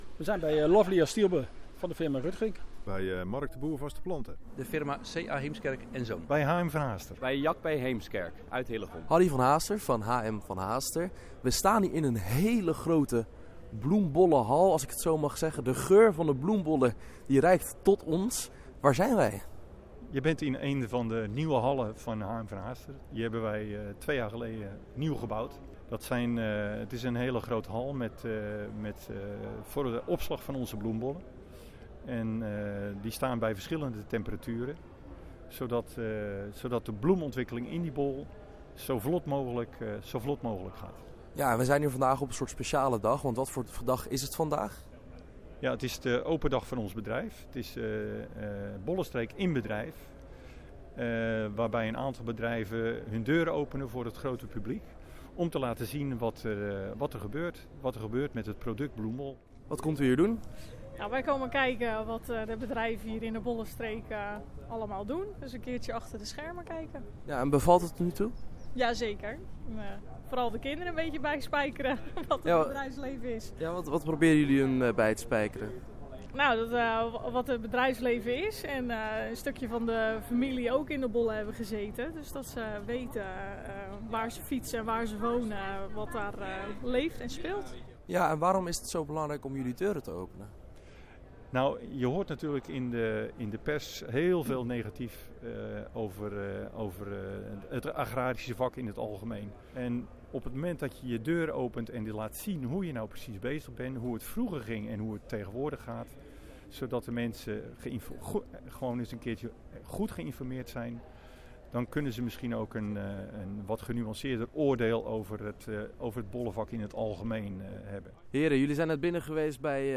Vijf agrarische bedrijven in Hillegom openden hun deuren voor het publiek, dat van dichtbij kon meemaken hoe de bedrijven te werk gaan, van teelt tot verwerking van bloemen en planten.